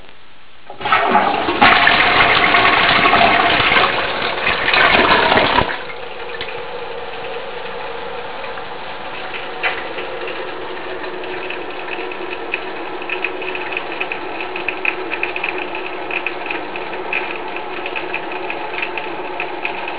my old fill valve sounded like running water after each flush. the new hydroclean 660 has a
completely different sound. it actually scared me at first, a loud gurgling noise, like a monster hiding in the toilet. after a few flushes though, i started getting used to it, and the tank fills up quickly, so the sound stop soon afterwards. hopefully once i get a new shutoff valve installed, i can control the water pressure and maybe dampen the noise a bit.
hydroclean_660_sound.wav